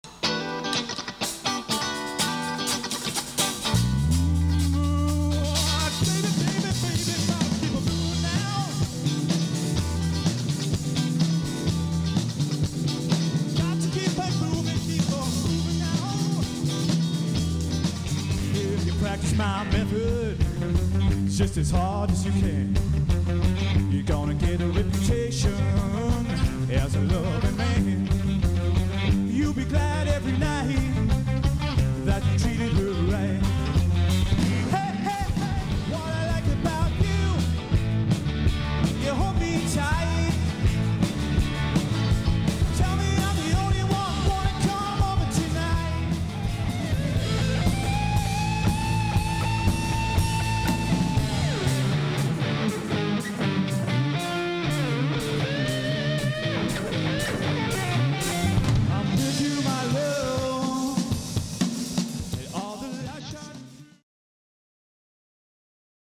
as well as current rock and dance anthems
vocals/ guitar
Bass guitar
drums/ Vocals